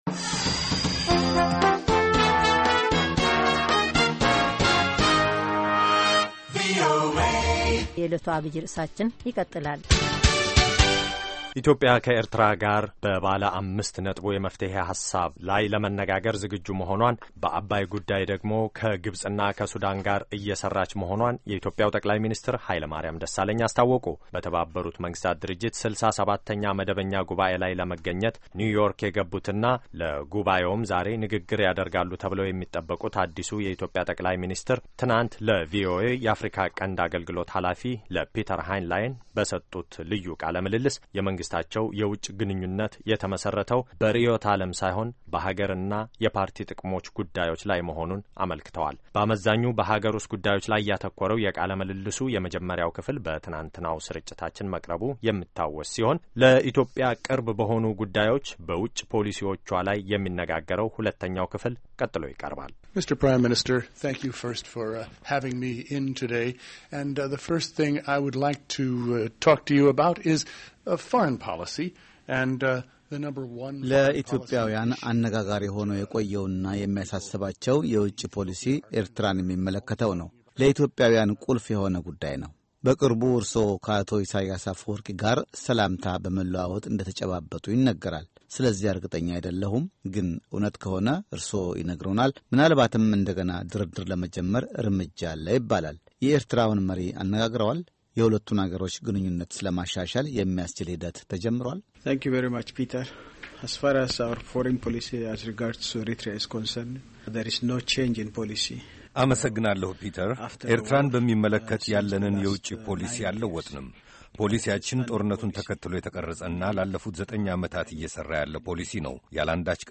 የጠ/ሚ ኃይለማርያም ደሣለኝ ልዩ ቃለ-ምልልስ ከቪኦኤ ጋር - ክፍል 2
Hailemariam Dessalegn Interview report Amharic.